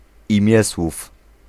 Ääntäminen
Ääntäminen France: IPA: [paʁ.ti.sip] Haettu sana löytyi näillä lähdekielillä: ranska Käännös Ääninäyte 1. imiesłów {m} Suku: m .